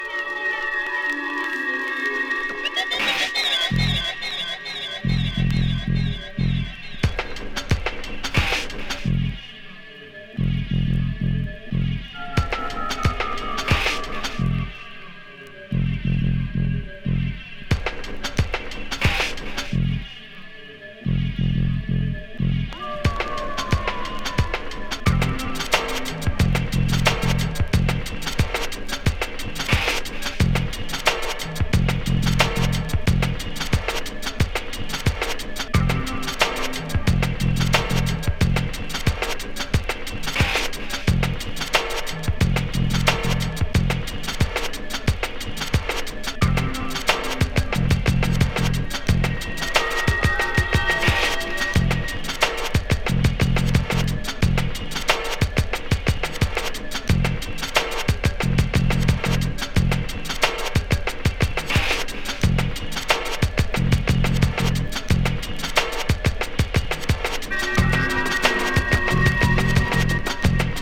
ファットなアブストラクトビート